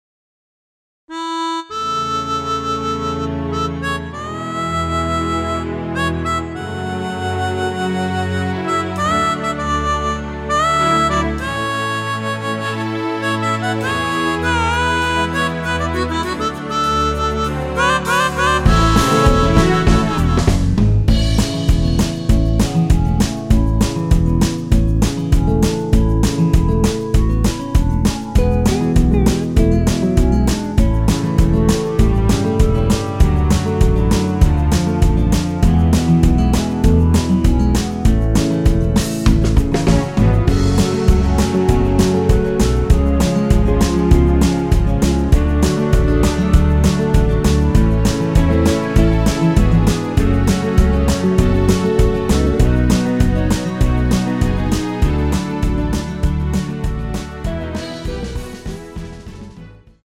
전주가 길어서 8마디로 편곡 하였으며
원키에서(-3)내린 (1절+후렴)으로 진행되는 멜로디 포함된 MR입니다.
앞부분30초, 뒷부분30초씩 편집해서 올려 드리고 있습니다.
중간에 음이 끈어지고 다시 나오는 이유는